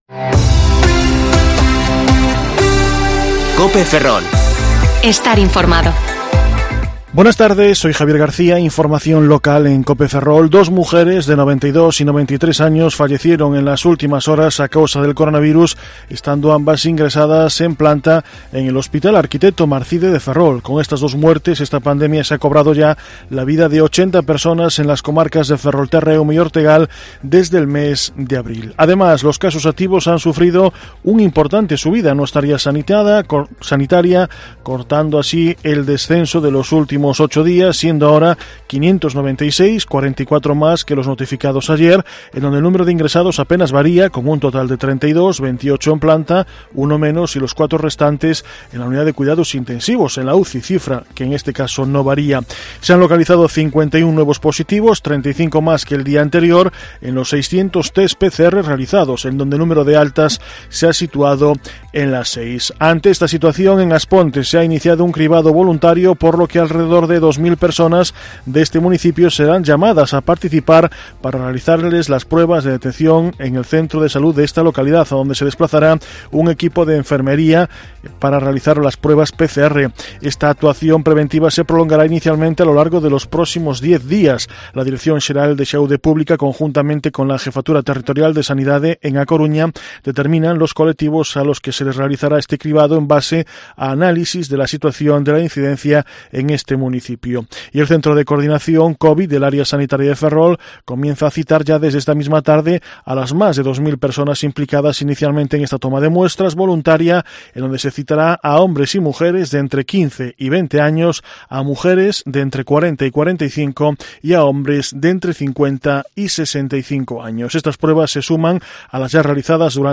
Informativo Mediodía COPE Ferrol 9/12/2020 (De 14,20 a 14,30 horas)